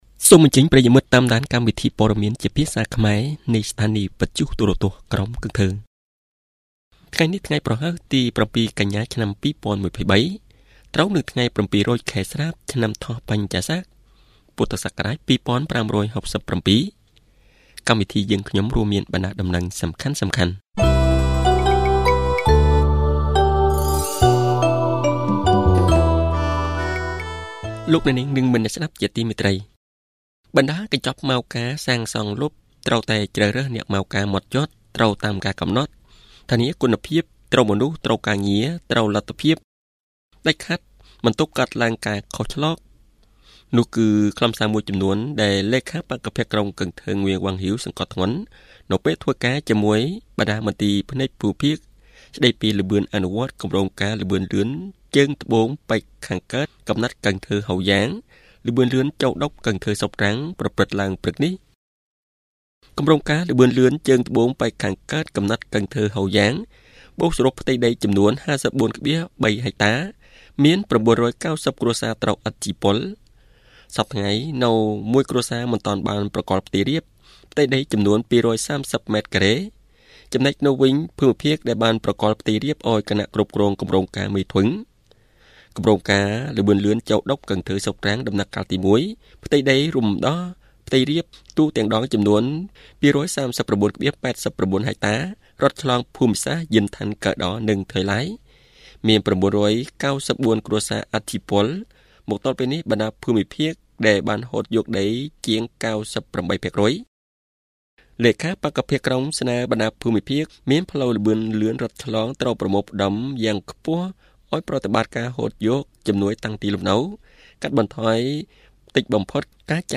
Bản tin tiếng Khmer tối 7/9/2023